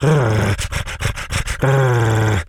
wolf_growl_06.wav